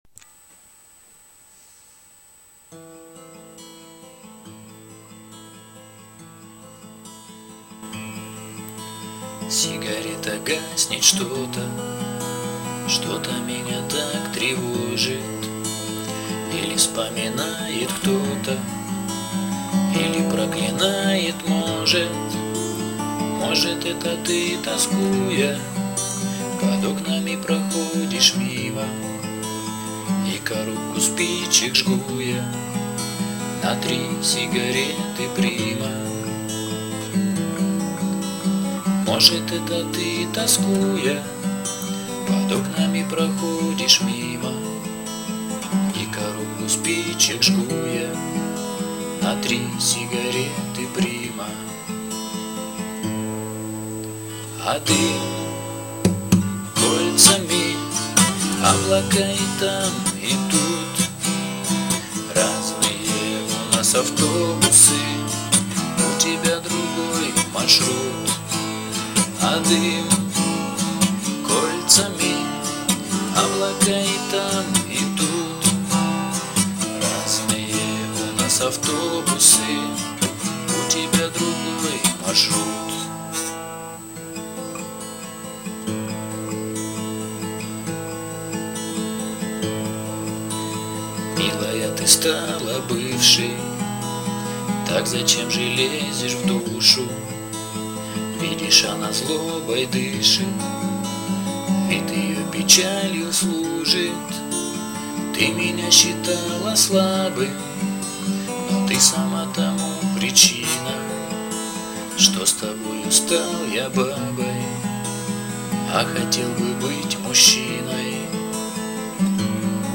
pod_gita_Dym_kolcami_dvorovaja_pesnja_pod_gita__im.mp3